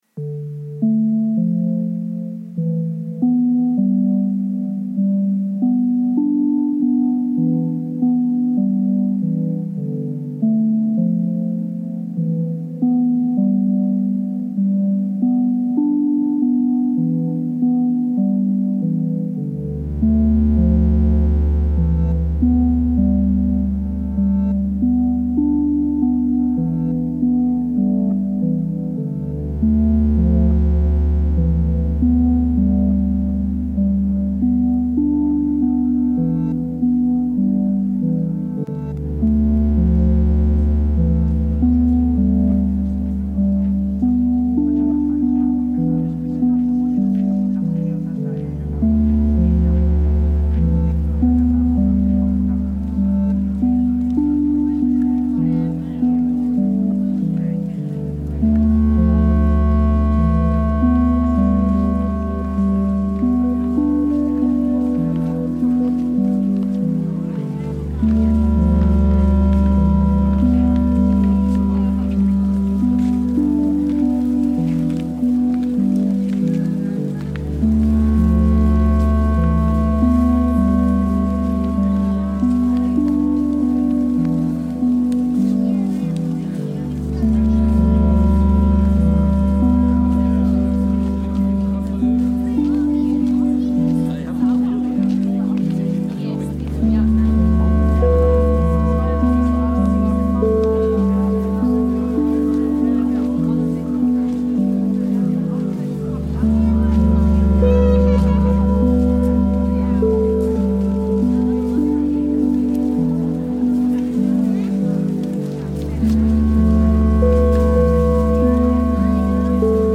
Thanin market, Chiang Mai reimagined